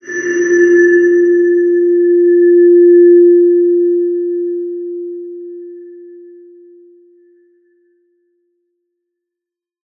X_BasicBells-F2-mf.wav